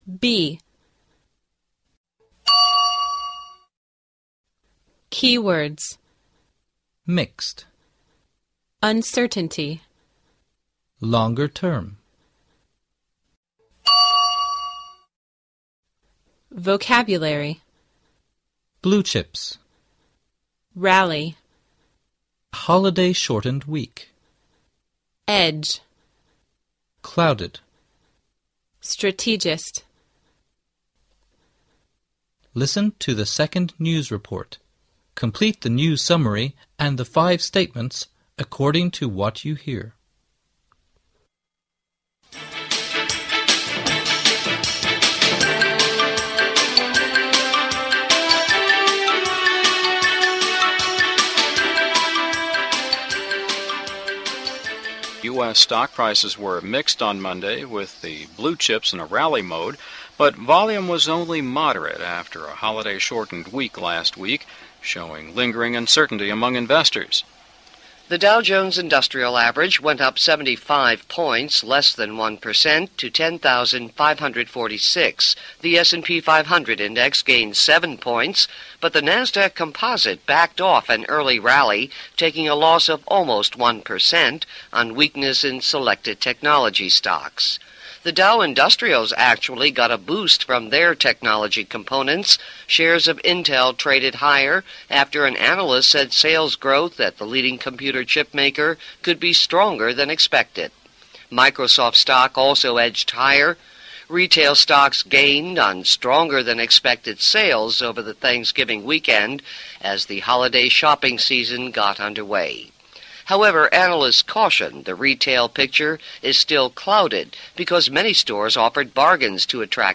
Listen to the second news report, complete the news summary and five statements according to what you hear.